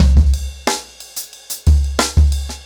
InDaHouse-90BPM.25.wav